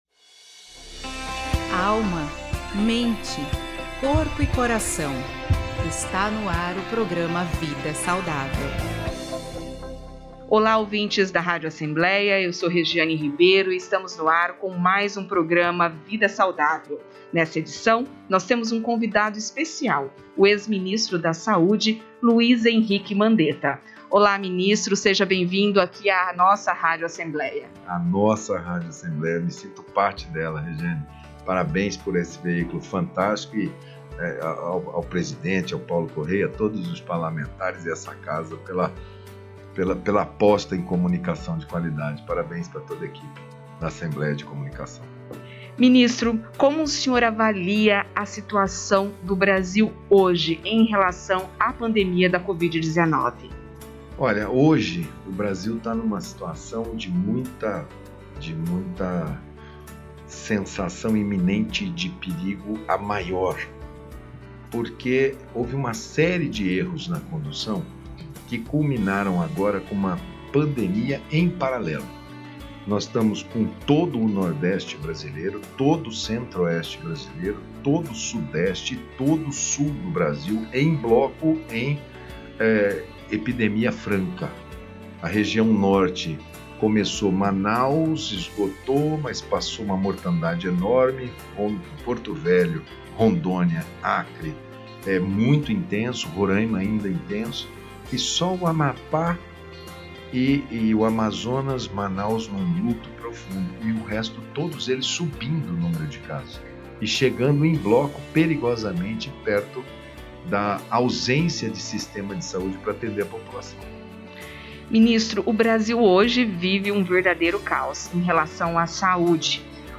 O ex-ministro da Saúde, Luiz Herique Mandetta, é o entrevistado desta edição do programa Vida Saudável da Rádio ALEMS, que vai ao ar nesta segunda-feira (15). Na pauta estão temas que destacam a situação do Brasil em relação a pandemia da covid-19, a nova cepa do vírus, a eficácia da vacina, entre outros assuntos.